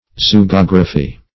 Search Result for " zoogeography" : The Collaborative International Dictionary of English v.0.48: Zoogeography \Zo`o*ge*og"ra*phy\, n. [Zoo- + geography.]
zoogeography.mp3